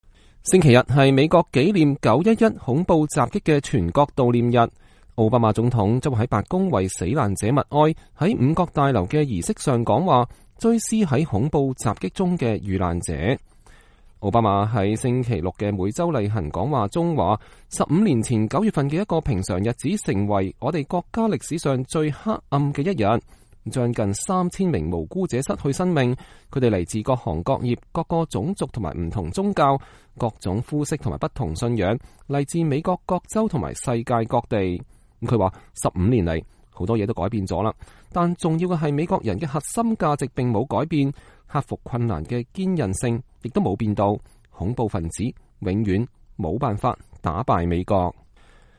星期天是美國紀念9-11的全國悼念日，奧巴馬總統在白宮為死難者默哀，在五角大樓的儀式上講話，追思在恐怖襲擊中的遇難者。